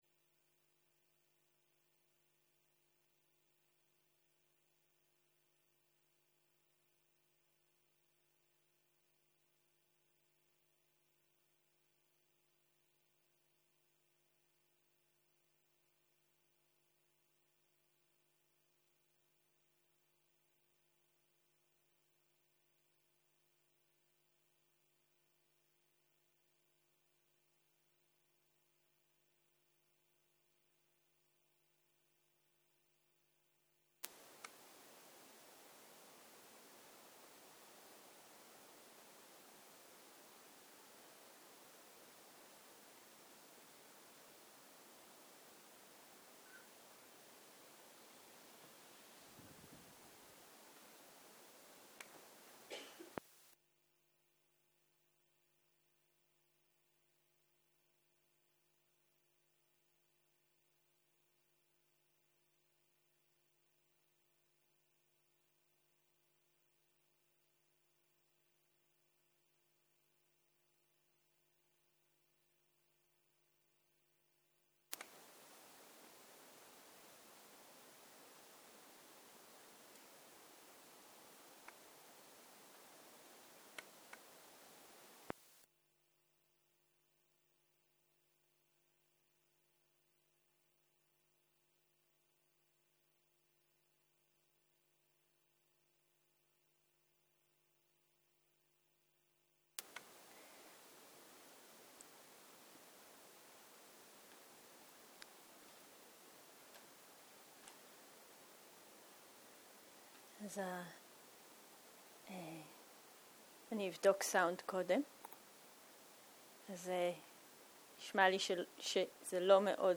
סוג ההקלטה: מדיטציה מונחית